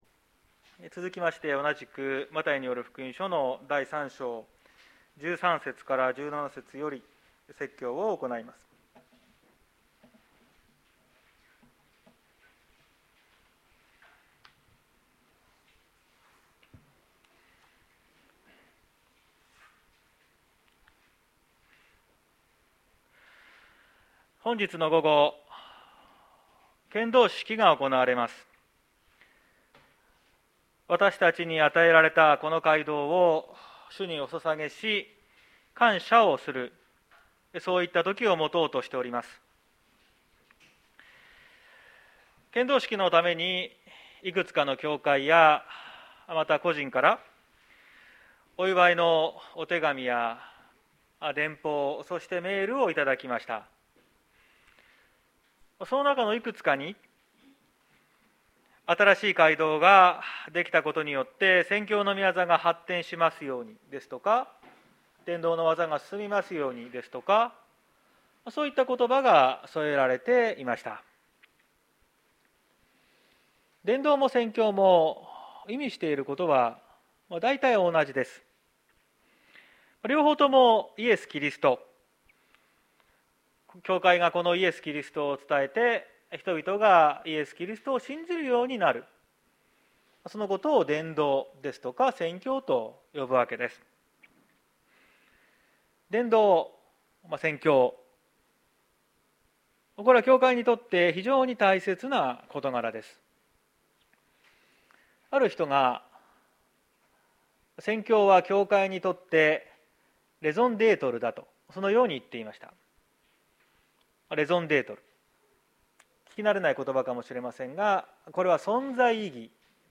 綱島教会。説教アーカイブ。